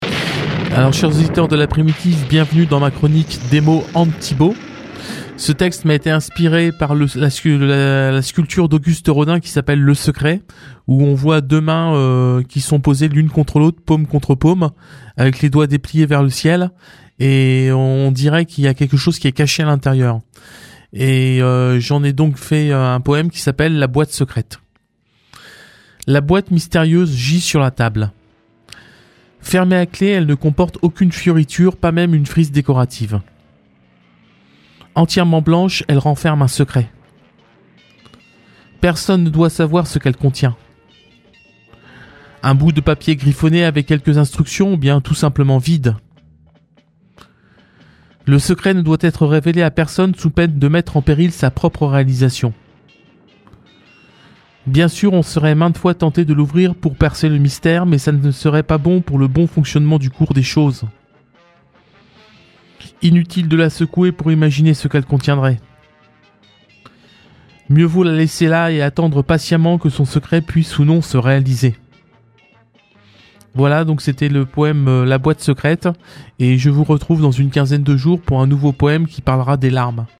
Chronique du 09 janvier